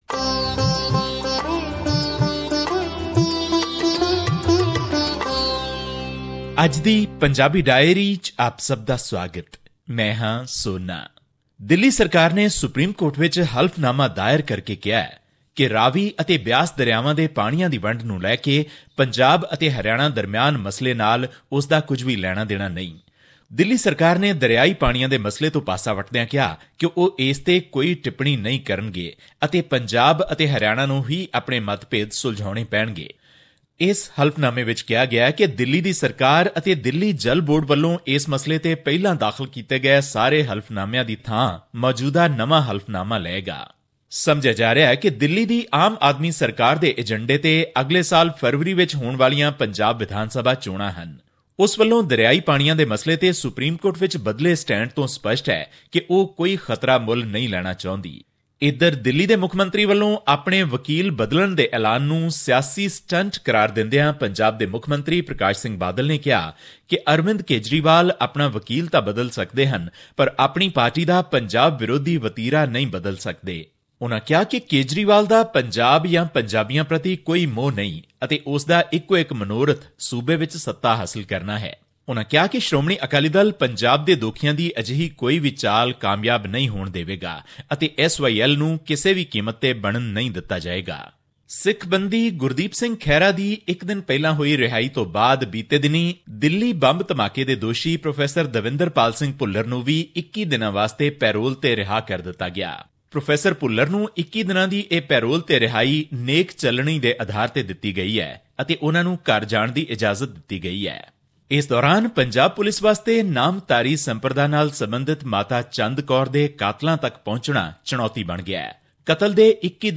Here's the podcast in case you missed hearing it on the radio.